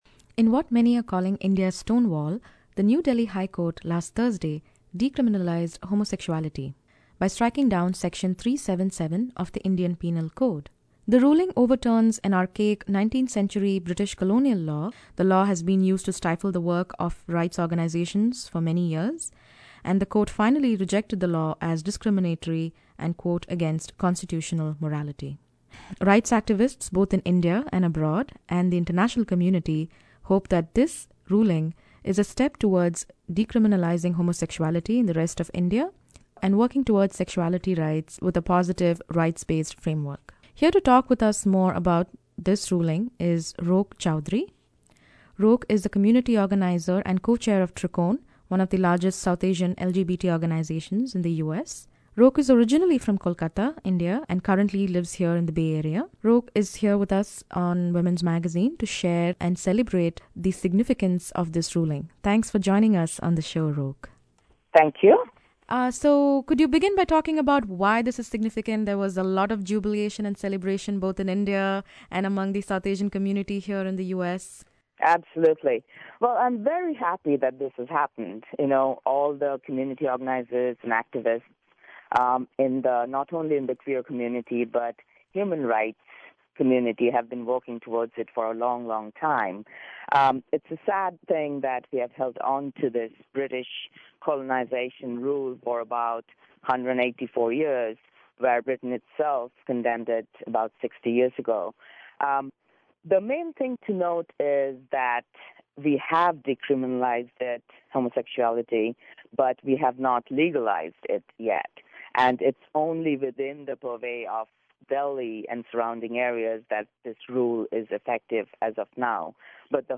India Decriminalizes Homosexuality: An Interview